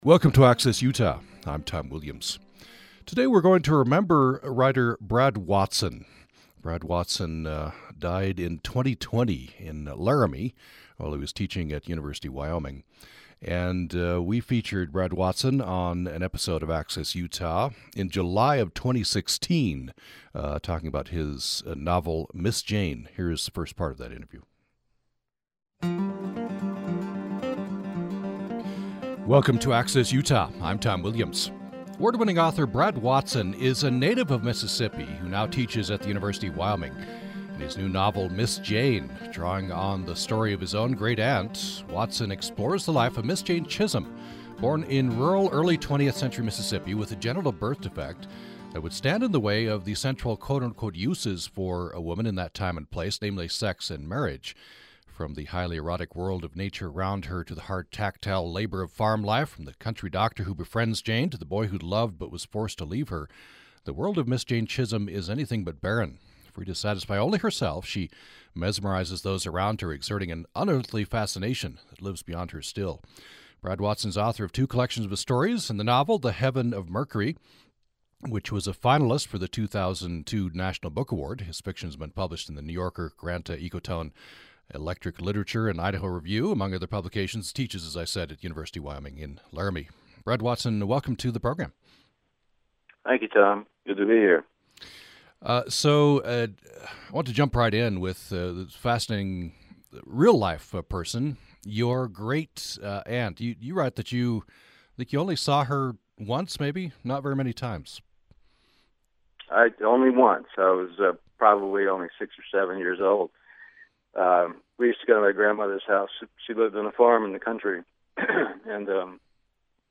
On this episode, we remember writer Brad Watson, who we interviewed in July 2016 about his novel "Miss Jane."